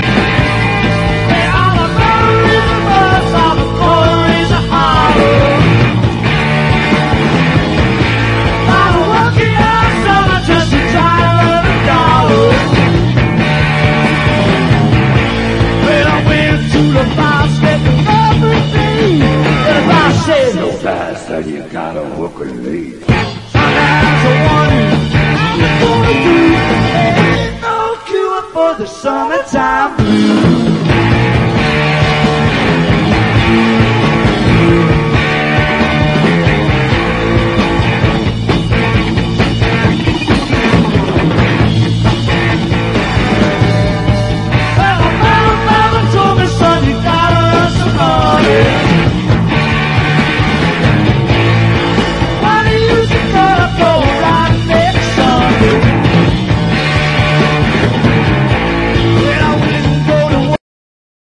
00'S PUNK / HARDCORE / POWER VIOLENCE
怒涛というほかない、アグレッシヴな音塊と咆哮の乱れ打ちに興奮しっぱなし！